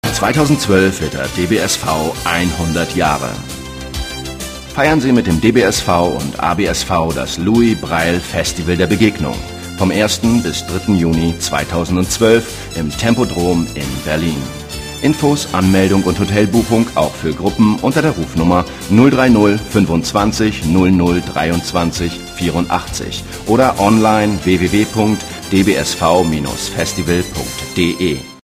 Sonore, markante und angenehme Stimme.
Sprechprobe: Werbung (Muttersprache):
Sonorous, distinctive and pleasant voice.